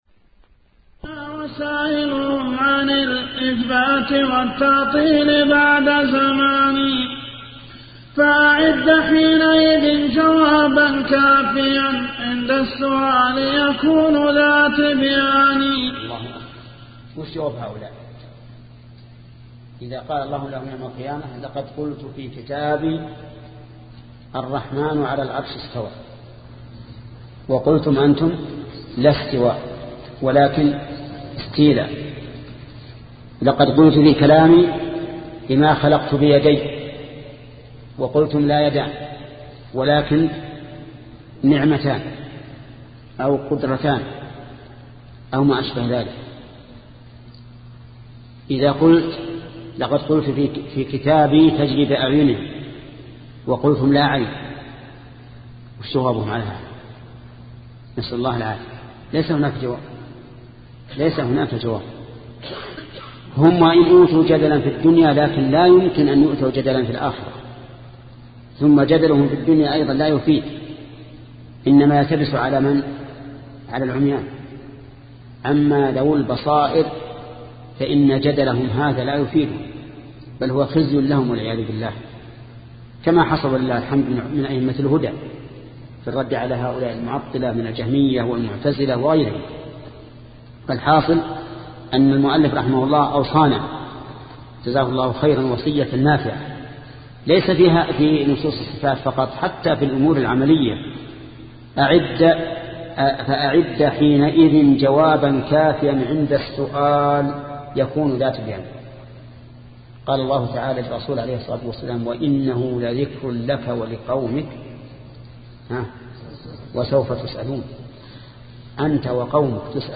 شبكة المعرفة الإسلامية | الدروس | التعليق على القصيدة النونية 38 |محمد بن صالح العثيمين